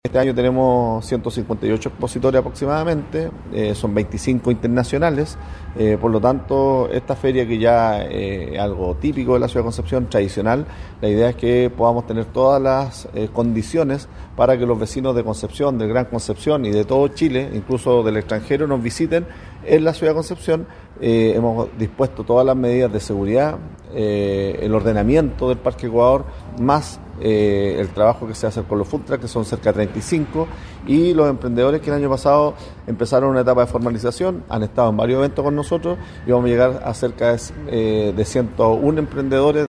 “La idea es que podamos tener todas las condiciones para que los vecinos de Concepción, del Gran Concepción, de Chile y del extranjero, nos visiten”, afirmó el jefe comunal.